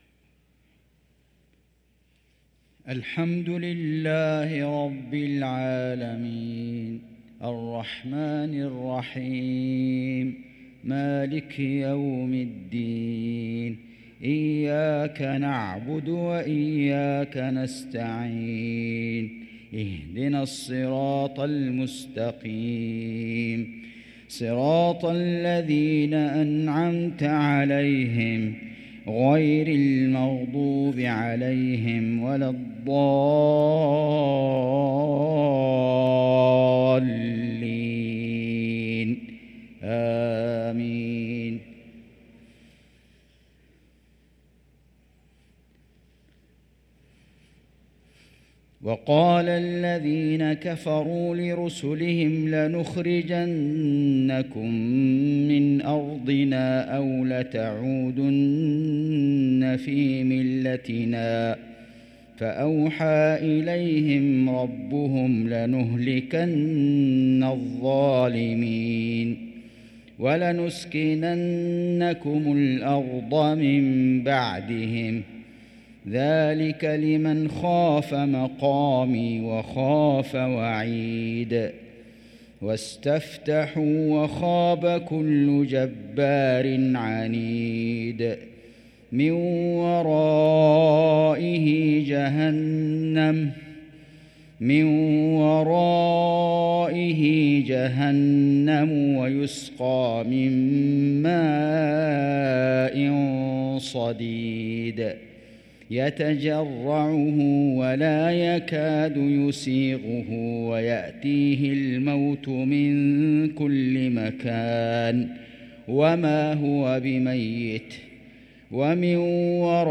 صلاة العشاء للقارئ فيصل غزاوي 26 ربيع الآخر 1445 هـ